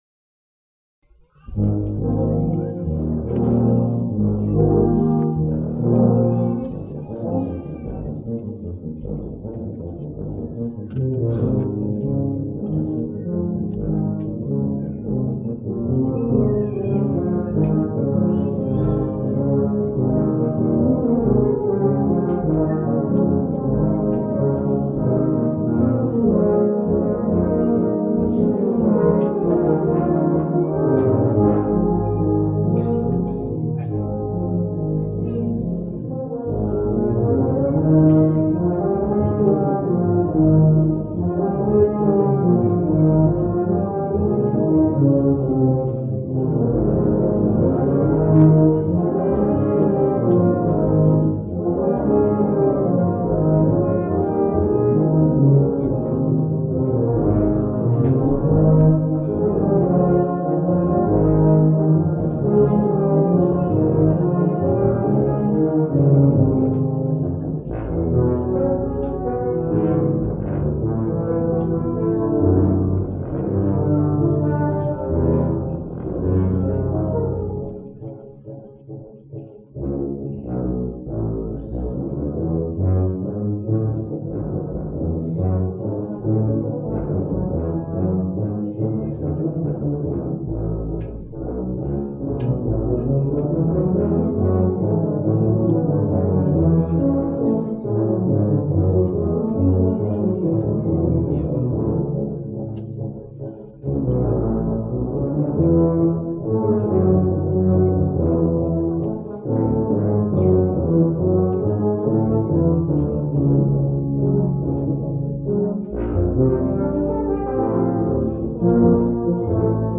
The sound quality is not the best since I was sitting 5 rows behind them on the stage, but it should give you a good idea.
12-tet
Tubas
Euphoniums
First_Noel-LATubaChristmas2006.mp3